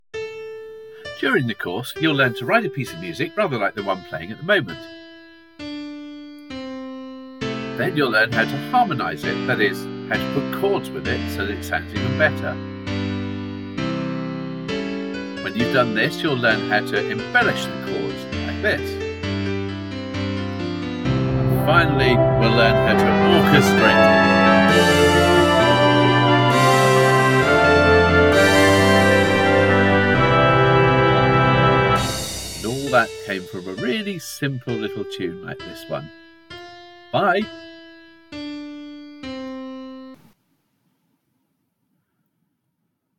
chorale
Bach’s innovative style